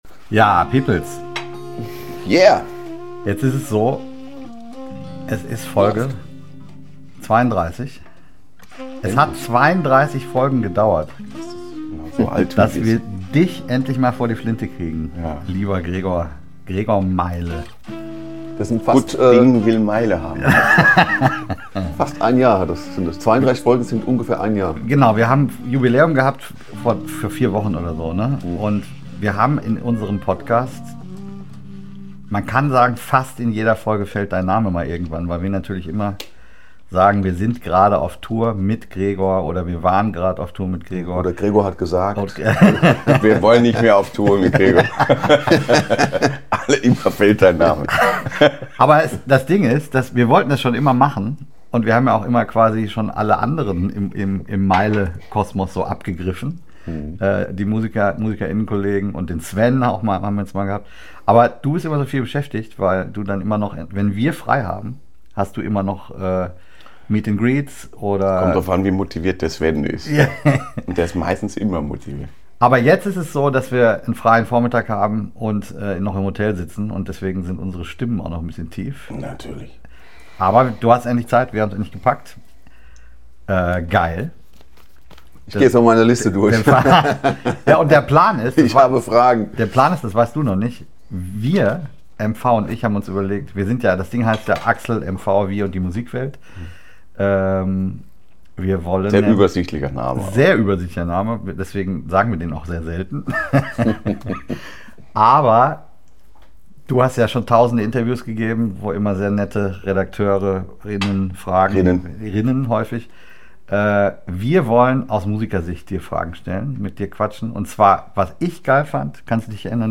das-gregor-meyle-interview-mmp.mp3